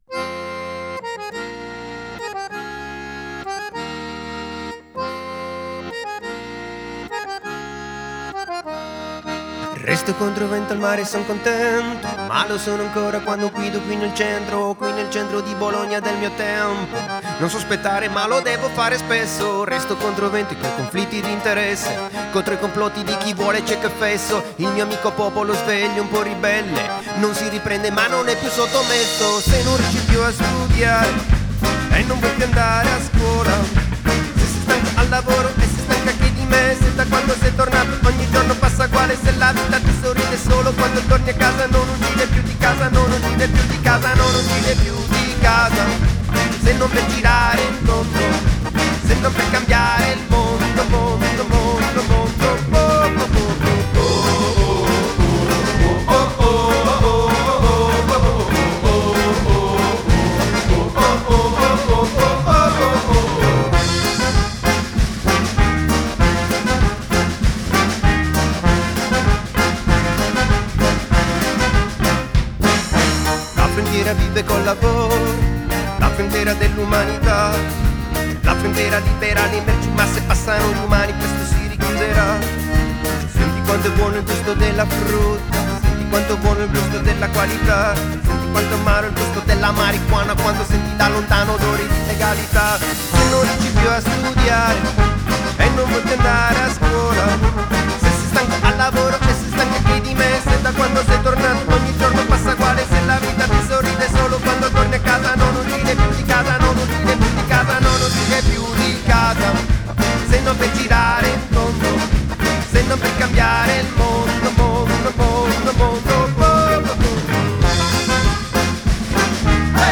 Il ritmo unico dello spaghetti samba da Bologna!